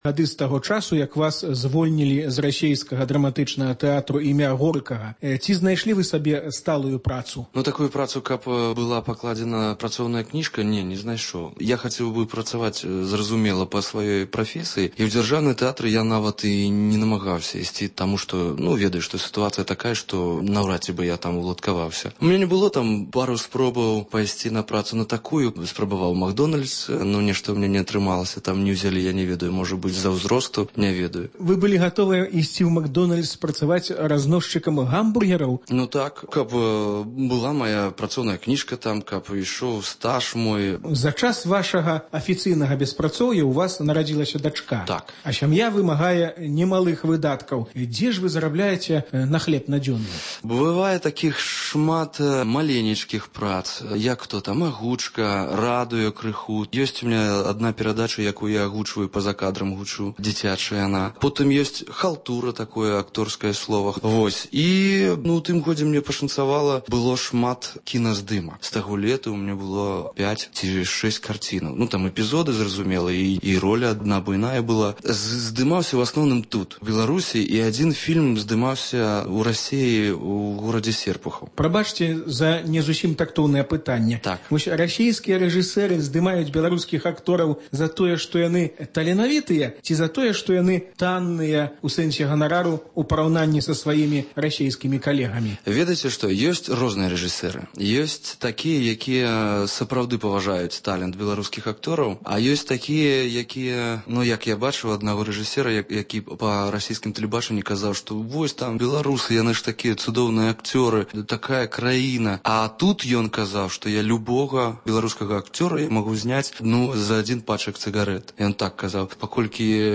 гутарыць з акторам